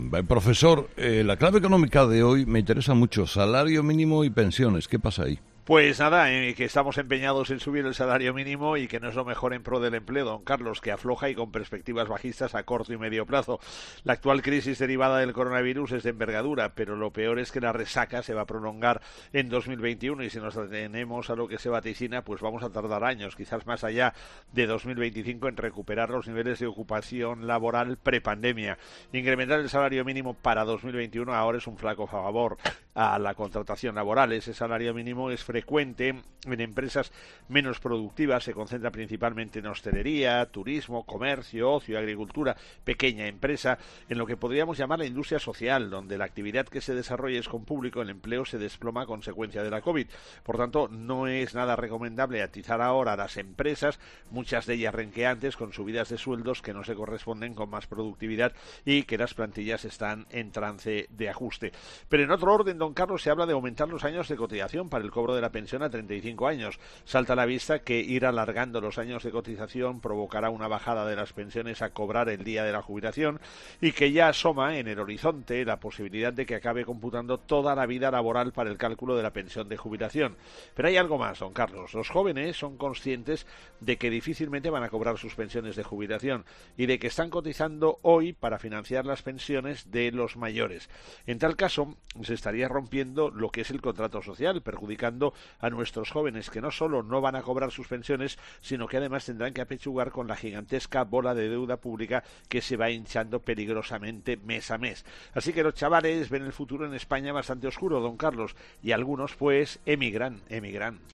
El profesor José María Gay de Liébana analiza en'Herrera en COPE’ las claves económicas del día.